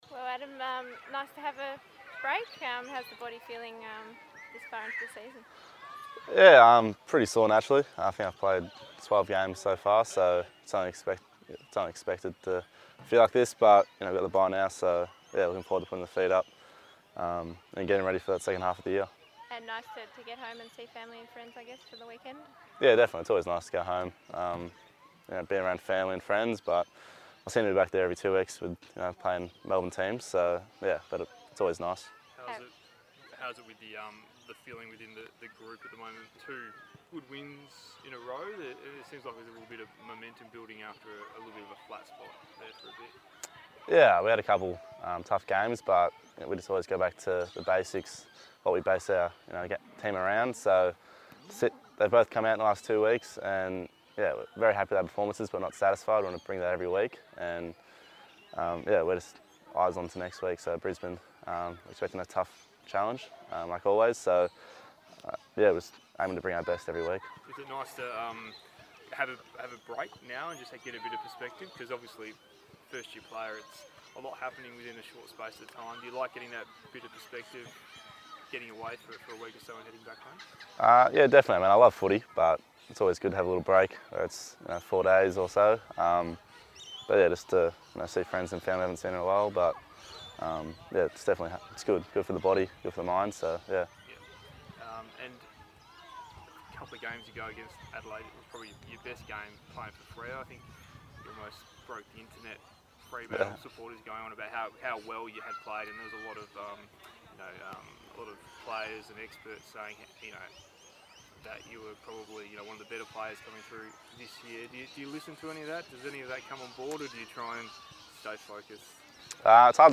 Adam Cerra media conference - 20 June 2018
Adam Cerra spoke to media during the bye.